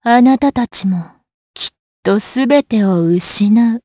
DarkFrey-Vox_Skill6_jp.wav